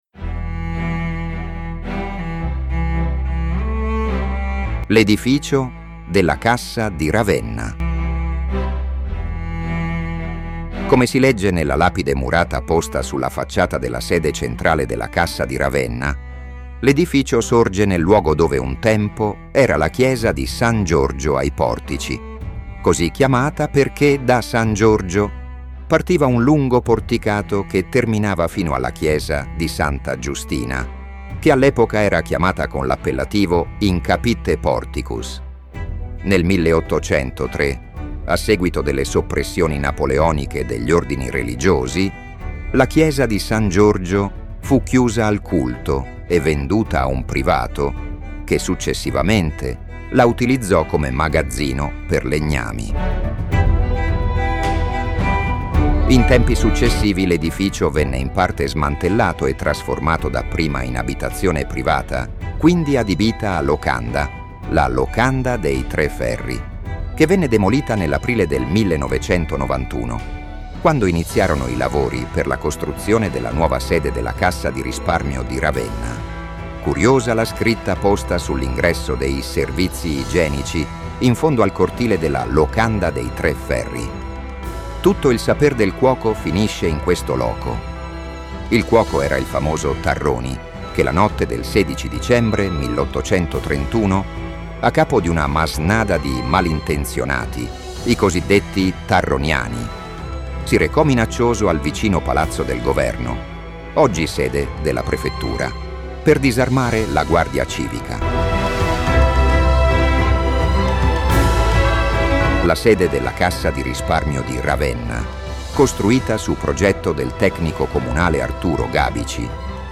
Audio_Guida
Voce: AI
L_edificio_della_Cassa_di_Ravenna_audioguida.mp3